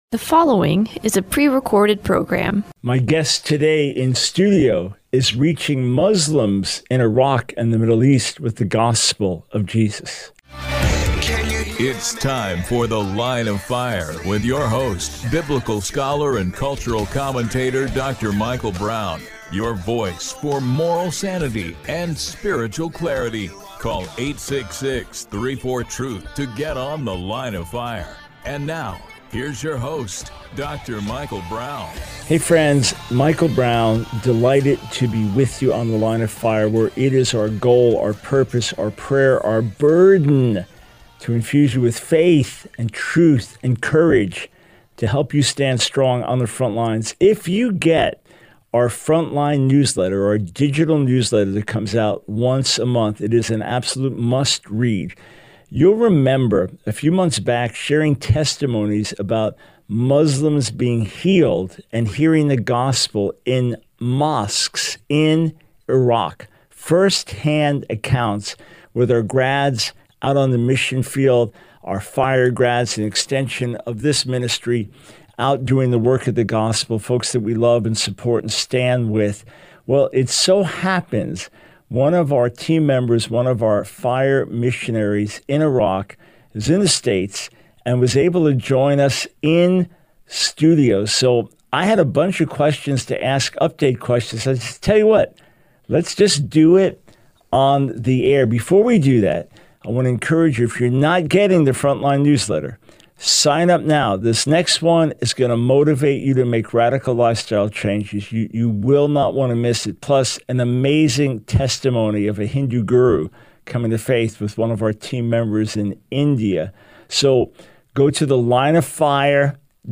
The Line of Fire Radio Broadcast for 08/14/24.